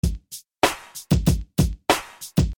放松的鼓点 123 BPM
描述：一个放松的drumpattern，我用大胆创建
Tag: 循环 节奏 Drumloop 鼓机